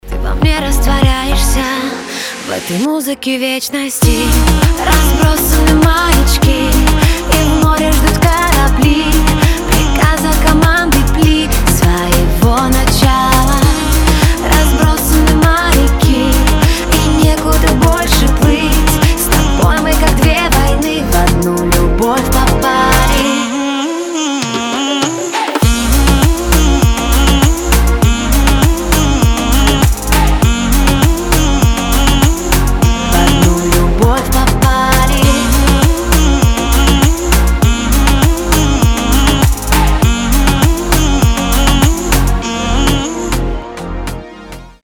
• Качество: 320, Stereo
поп
Саксофон